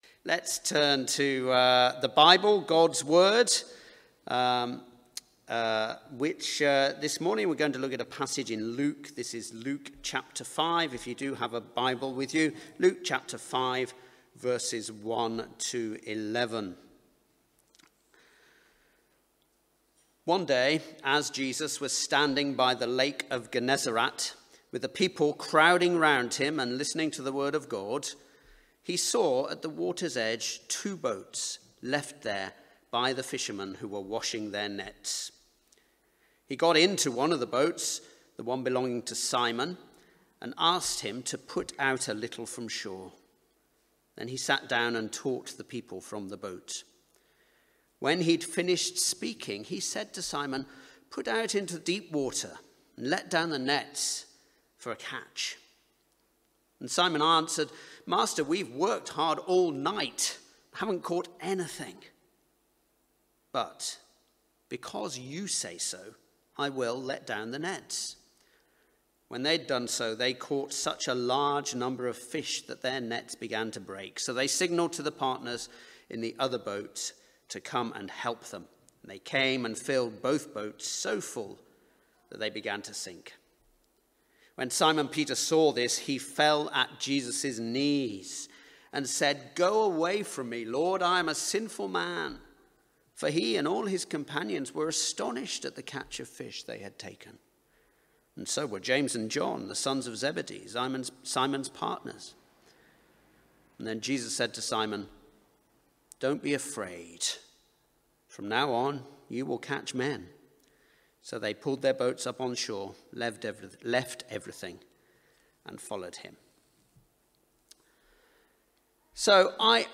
Baptismal Service – Into the Deep – Luke 5:1-11